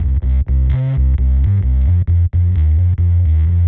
Index of /musicradar/dub-designer-samples/130bpm/Bass
DD_PBassFX_130C.wav